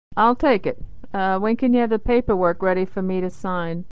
Unstressed 'for' is reduced = /fər/